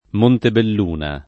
[ montebell 2 na ]